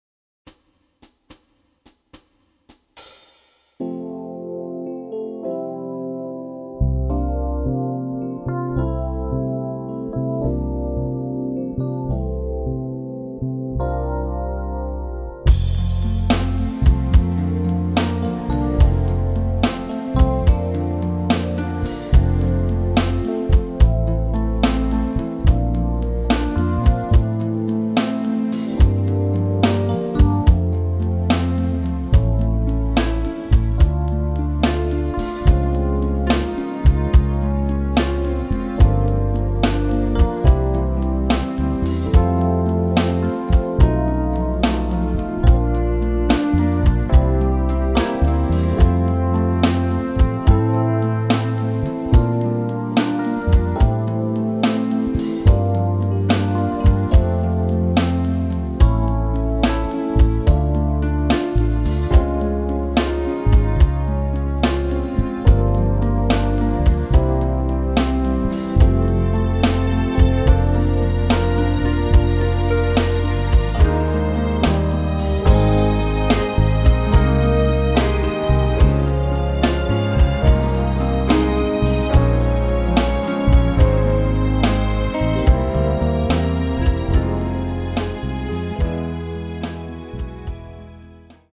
Real endings. Never a fadeout.